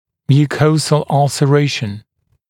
[mjuː’kəuzl ˌʌlsə’reɪʃn][мйу:’коузл ˌалсэ’рэйшн]изъязвление слизистой оболочки